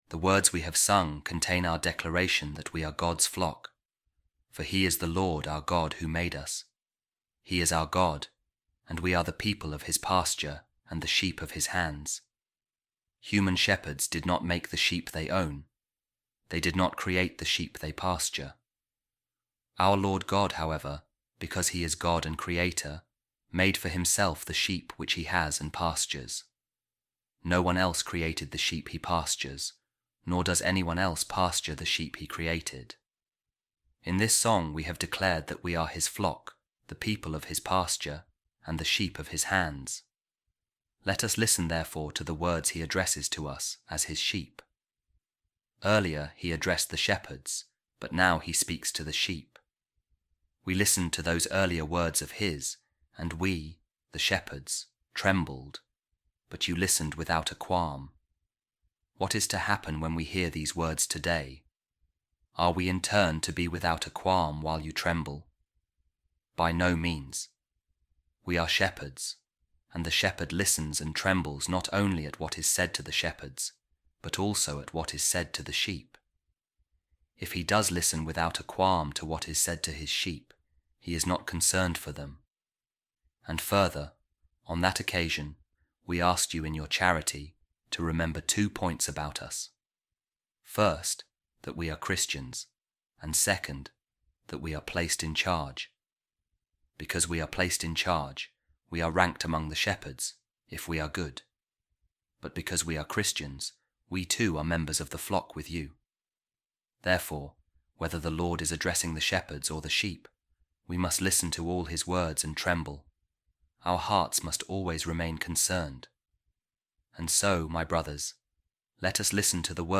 Audio Daily Bible
A Reading From The Sermons Of Saint Augustine | He Is Our God And We Are The Sheep Of His Flock